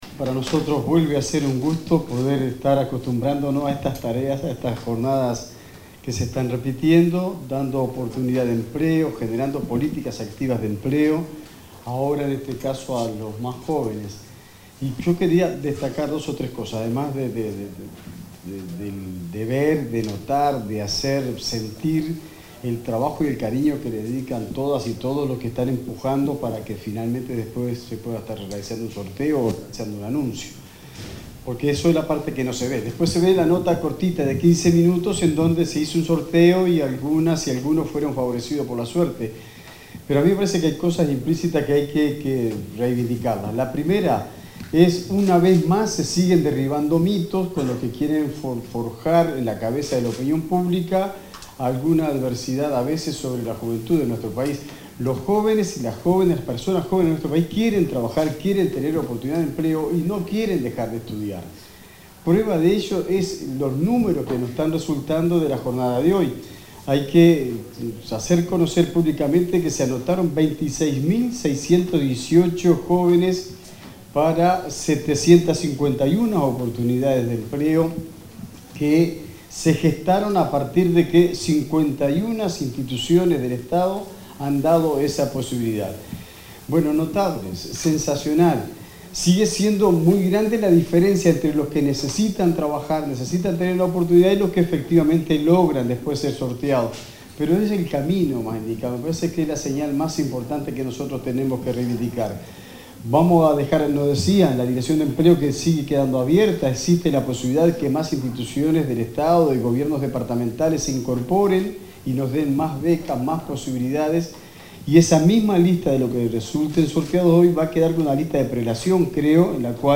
Palabras del ministro de Trabajo y Seguridad Social, Juan Castillo
Palabras del ministro de Trabajo y Seguridad Social, Juan Castillo 29/10/2025 Compartir Facebook X Copiar enlace WhatsApp LinkedIn Este 29 de octubre, se realizó el sorteo correspondiente al programa Yo Estudio y Trabajo 2025. En la oportunidad, el titular del Ministerio de Trabajo y Seguridad Social, Juan Castillo, se expresó al respecto.